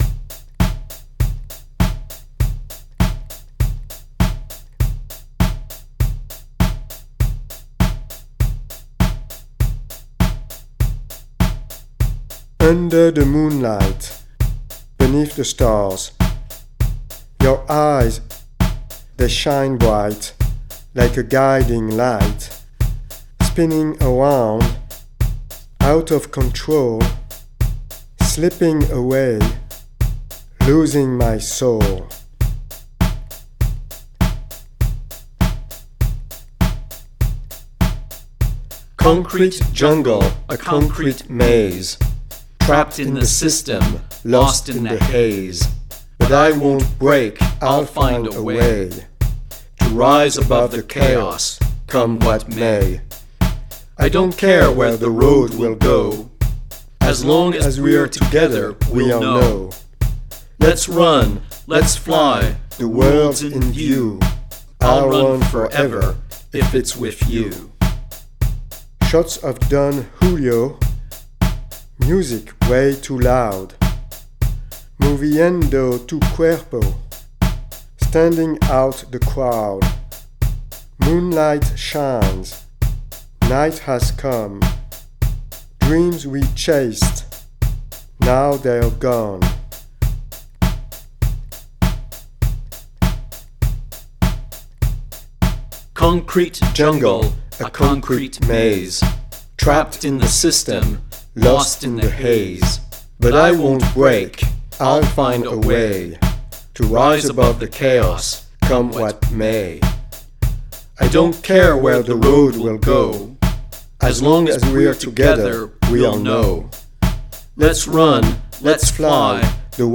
drum machine
Chorus vocals on tracks 1